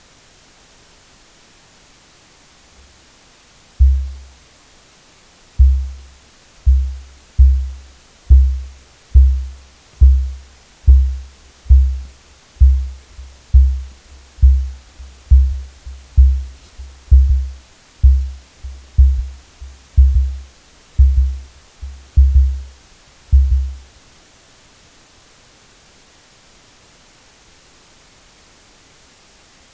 Звуки включают крики, шум шагов и другие характерные для эму аудиоэффекты.
Звуки эму из дикой природы Австралии напоминают стук